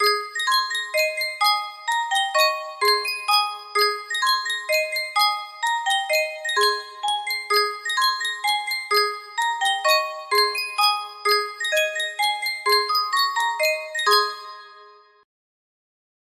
Sankyo Music Box - Mary Had a Little Lamb AQ music box melody
Sankyo Music Box - Mary Had a Little Lamb AQ
Full range 60